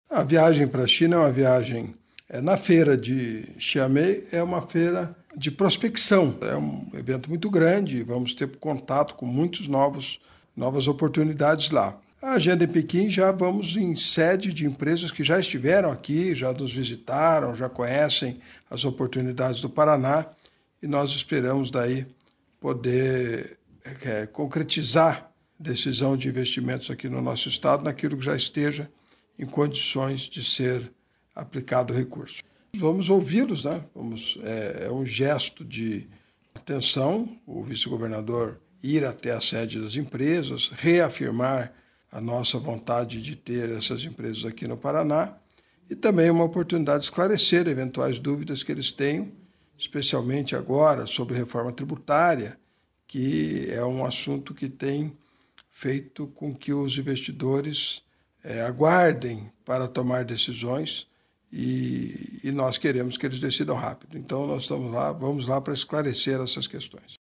Sonora do secretário da Indústria, Comércio e Serviços, Ricardo Barros, sobre a missão do Paraná à China em busca de investimentos